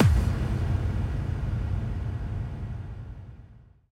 FXkick.ogg